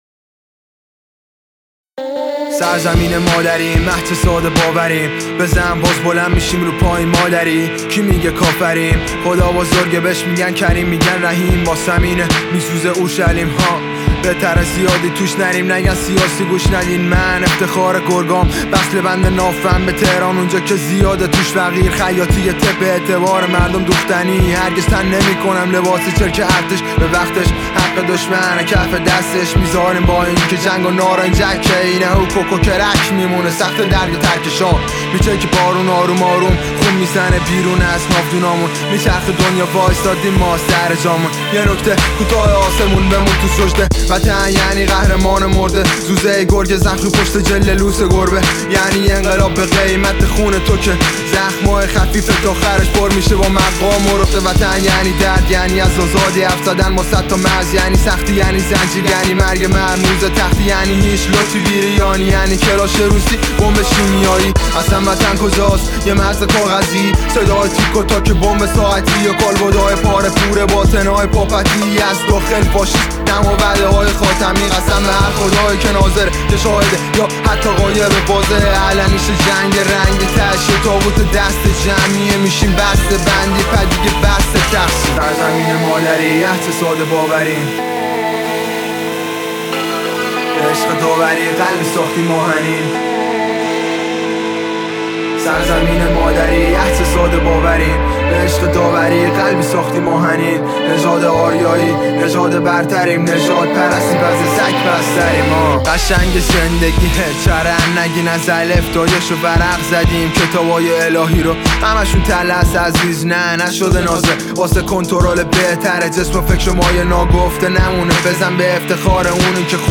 رپ و هیپ هاپ